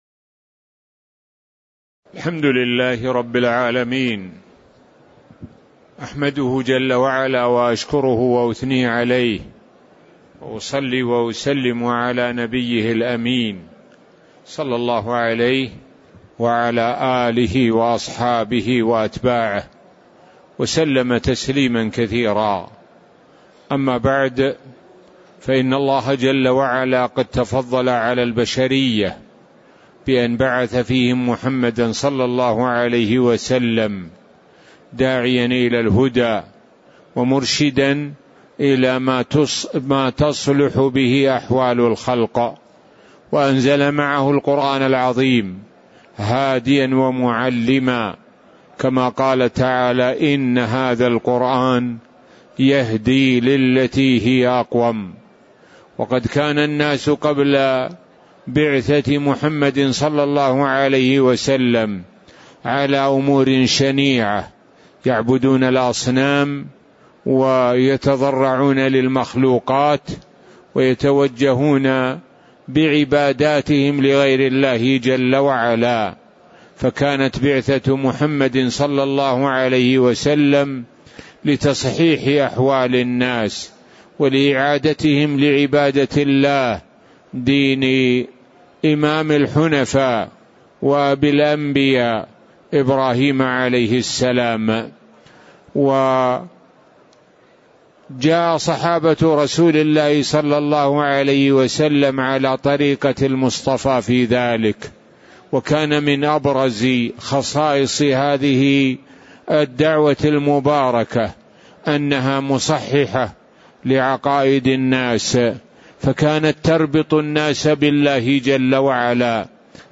تاريخ النشر ١٥ ذو القعدة ١٤٤٣ هـ المكان: المسجد النبوي الشيخ: معالي الشيخ د. سعد بن ناصر الشثري معالي الشيخ د. سعد بن ناصر الشثري المقدمة (01)